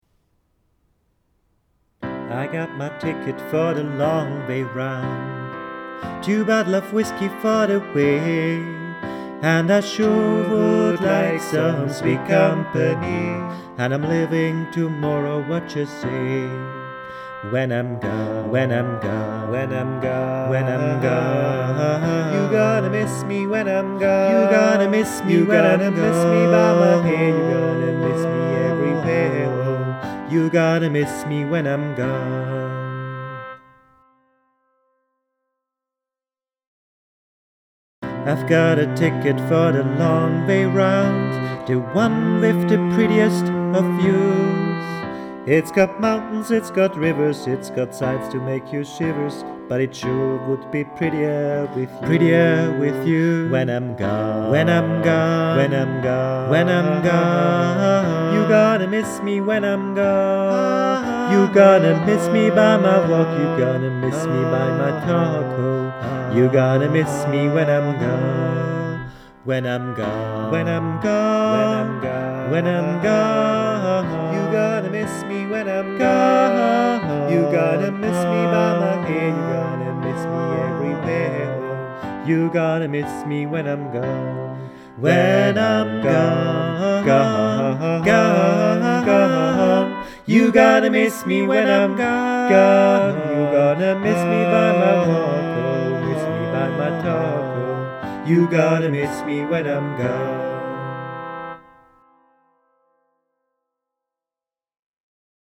Alles gesungen (mit Begleitung)